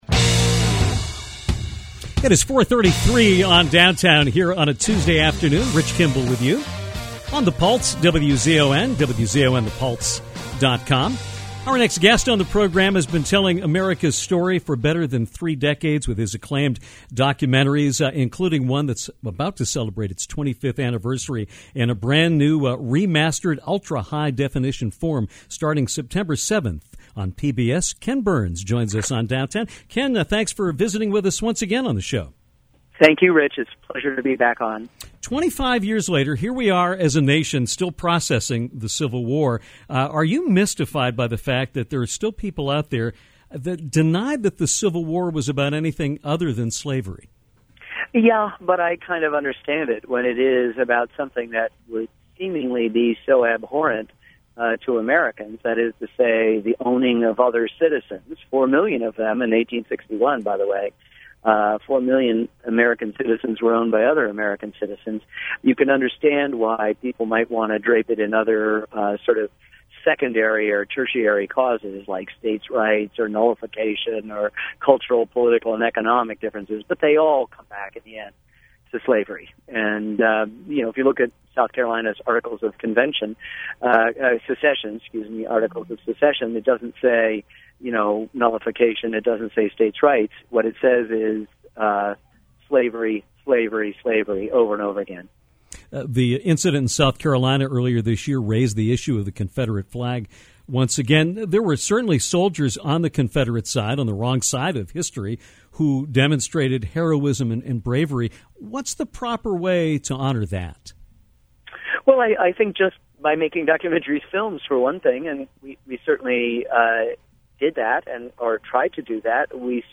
Renowned documentarian Ken Burns joined Downtown on Tuesday afternoon to talk about the 25th anniversary of his series on the Civil War. Burns discussed why he feels some people view the Civil War today as more than just a war about slavery.